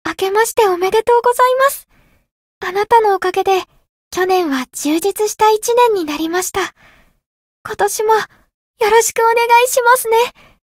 灵魂潮汐-梦咲音月-春节（相伴语音）.ogg